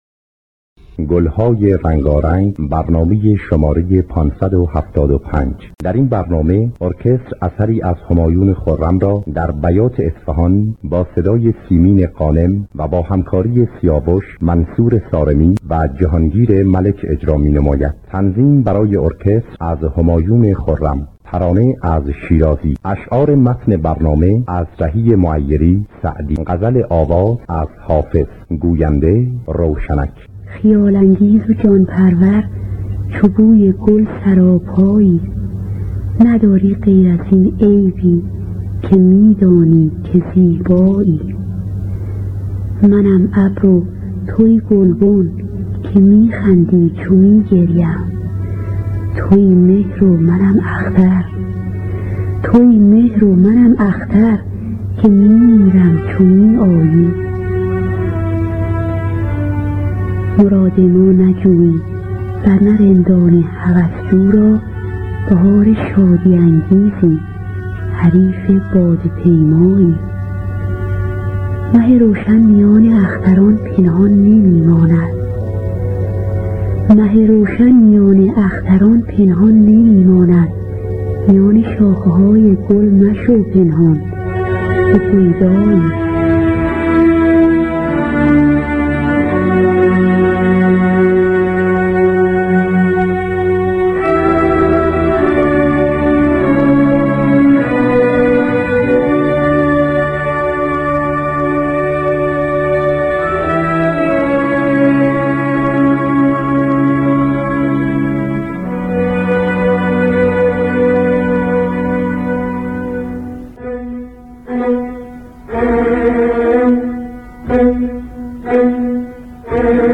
دانلود گلهای رنگارنگ ۵۷۵ با صدای سیمین غانم، محمدرضا شجریان در دستگاه بیات اصفهان. آرشیو کامل برنامه‌های رادیو ایران با کیفیت بالا.